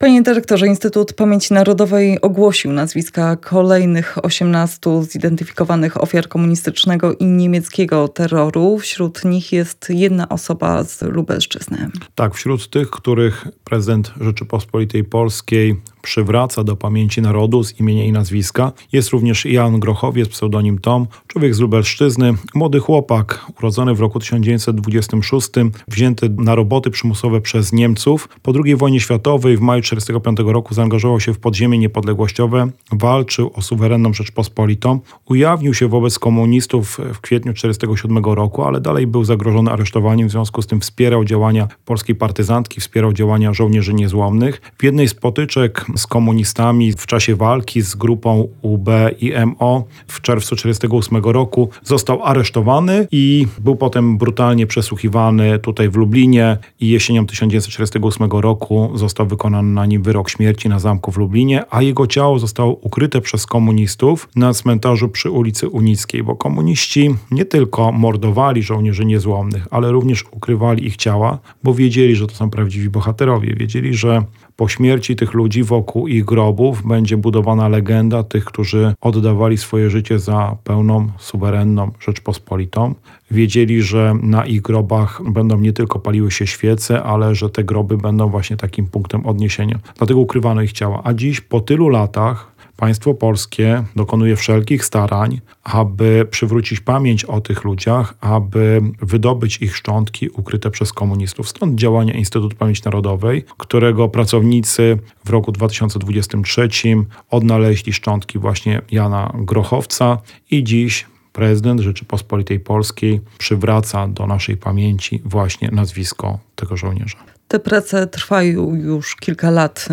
Rozmowa z dyrektorem lubelskiego IPN Robertem Derewendą